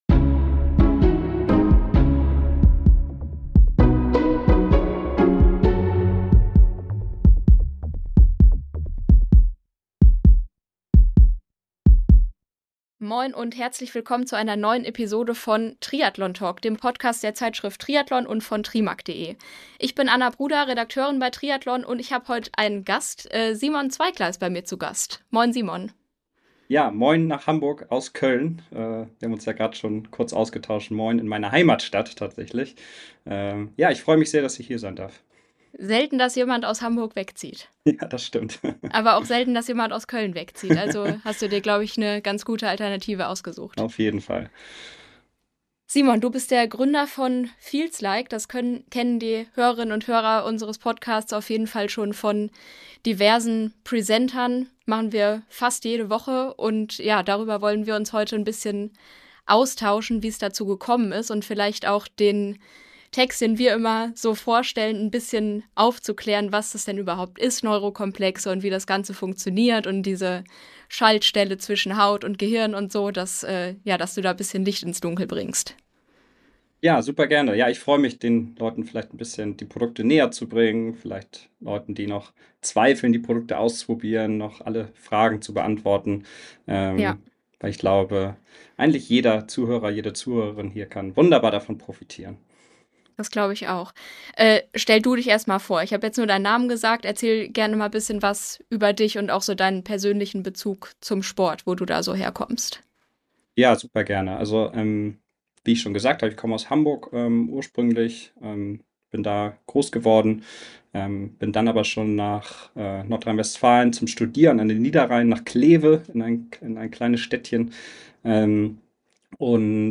Zwei Experten aus der Redaktion sprechen über das aktuelle Triathlongeschehen.